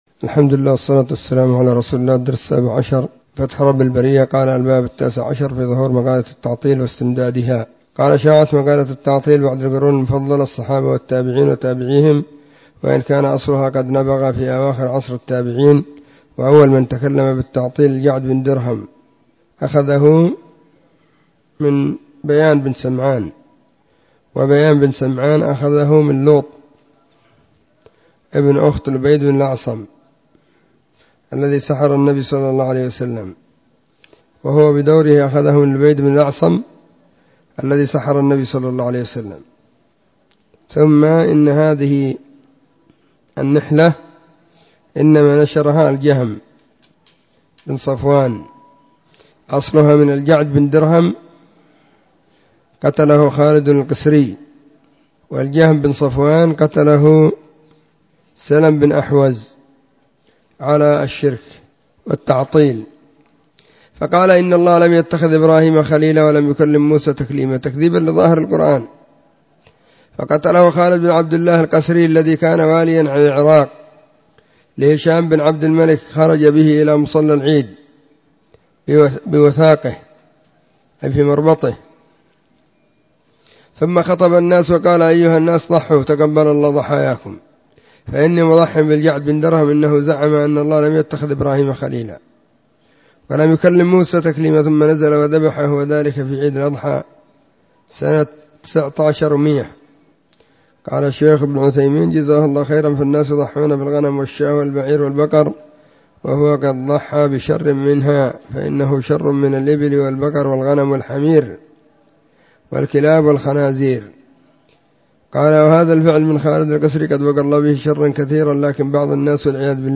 📢 مسجد الصحابة – بالغيضة – المهرة، اليمن حرسها الله.